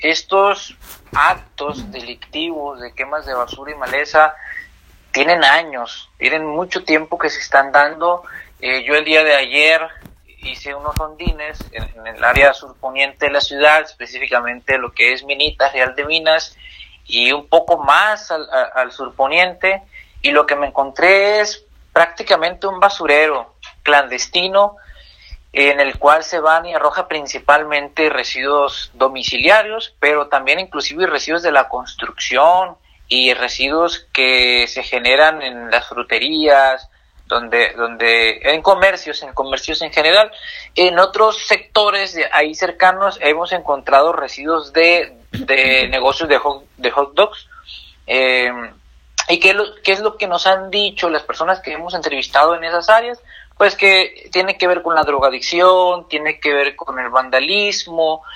hablaron en entrevista para Proyecto Puente sobre esta problemática que azota Hermosillo año tras año y que sigue sin resolverse.